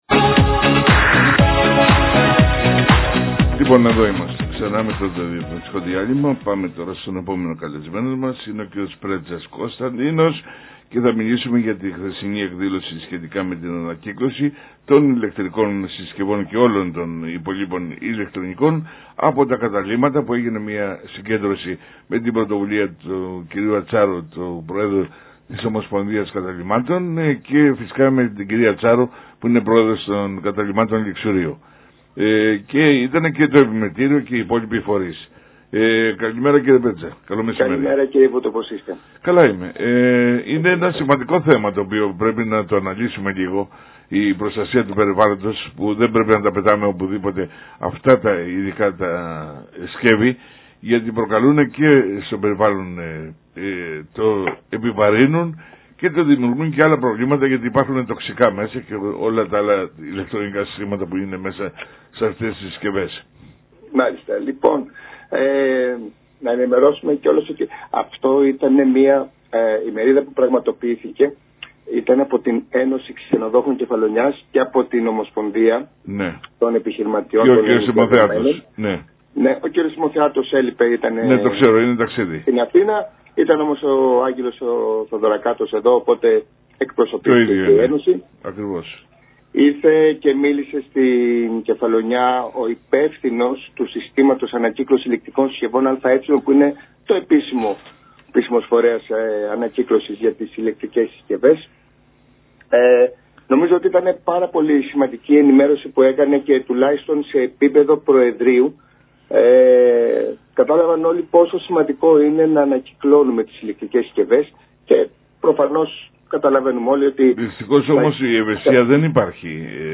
Εκπομπή το Βημα του Πολίτη στο Νησί 93,9 fm 6/4/3023 – Ανεξάρτητος